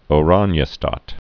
(ō-ränyə-stät)